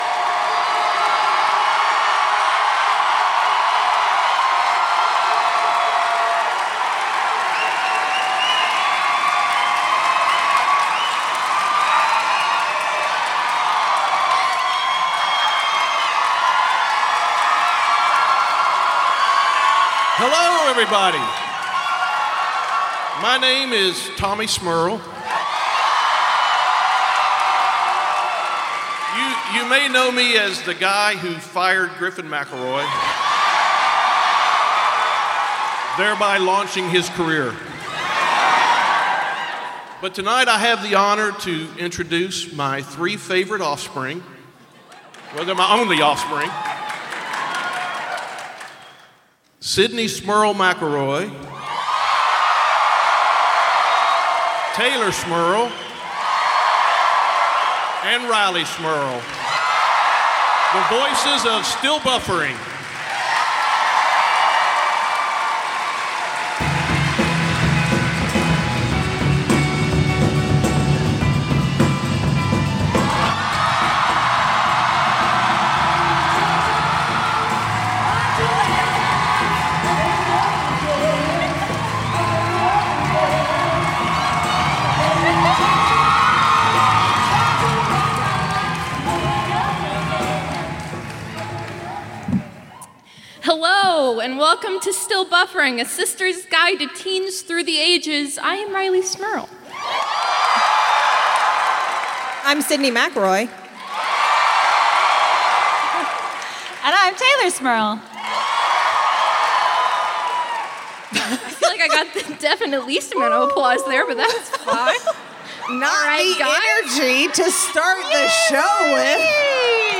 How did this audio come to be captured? After a whirlwind weekend away in the greatest city in the world, the sisters are proud to present their most recent live show from Brooklyn, all about pictures and VSCO girls and sksksksk, and I oop?